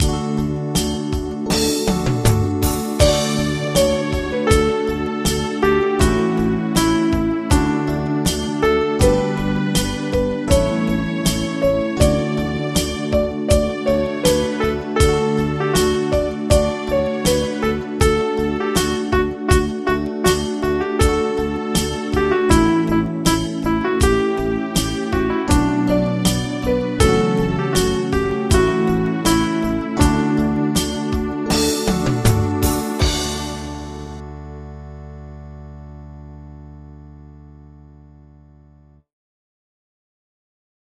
Audio Midi Bè Sop: download